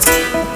PIANOLOOP1-L.wav